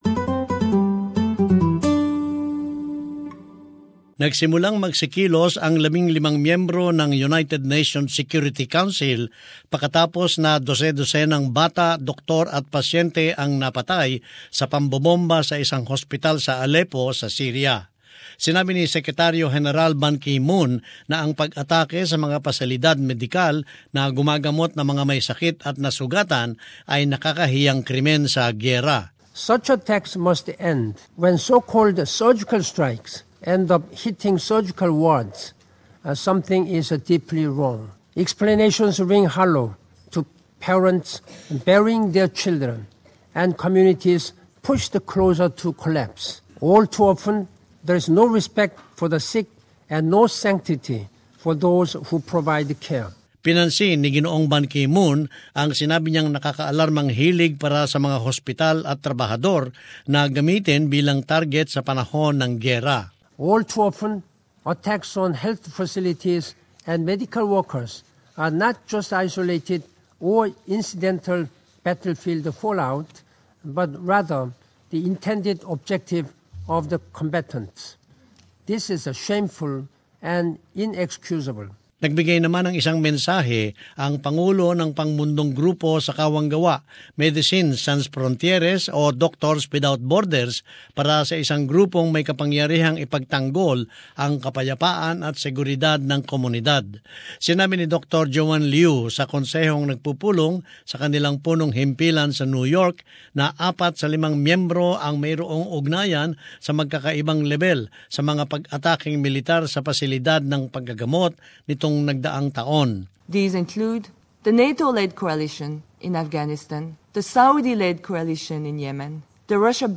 As this report shows, the international charity Médecins Sans Frontières says most of the Security Councils five permanent members have been linked to deadly attacks on medical facilities.